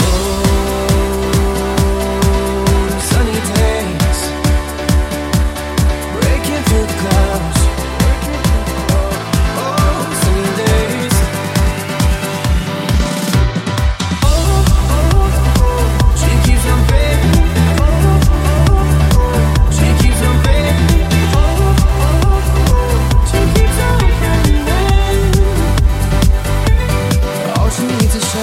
deep house
hits , new releases & remix
Genere: house, deep house, remix